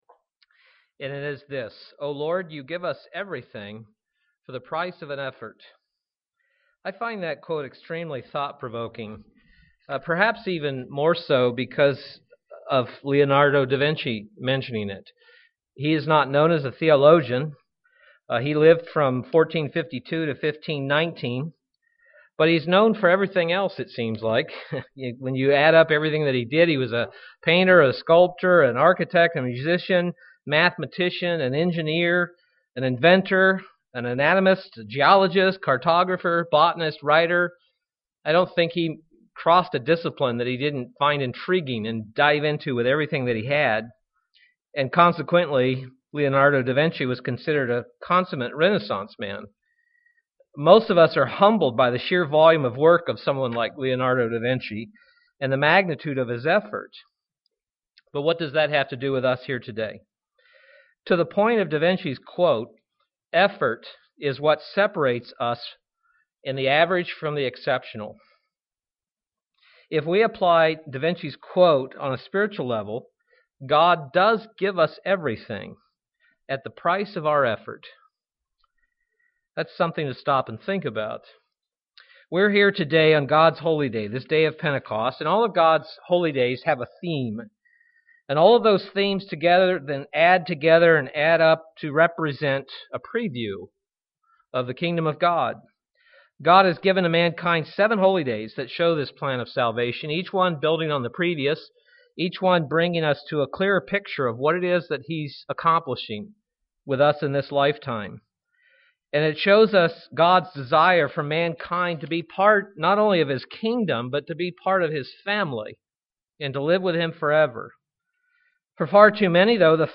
How do we develop and become stronger in God’s Holy Spirit? This message was given on the Feast of Pentecost.
UCG Sermon Studying the bible?